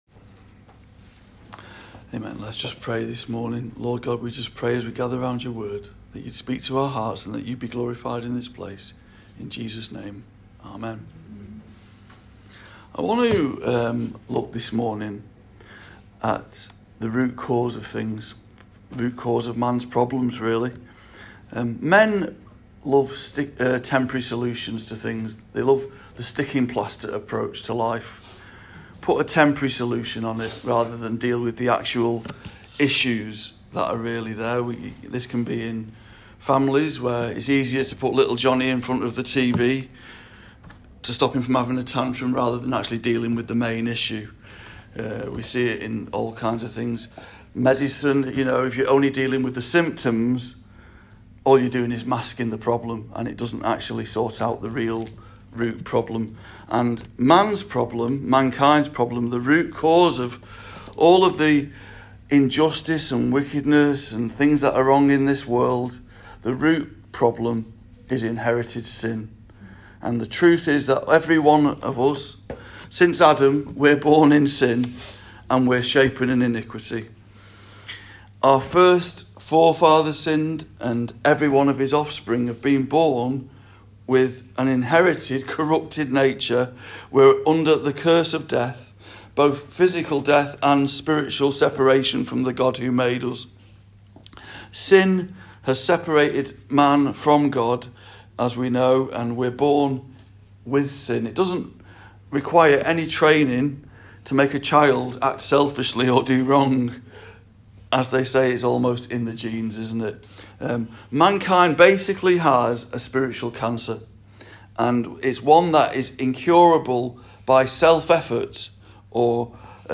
Calvary Chapel Warrington Sermons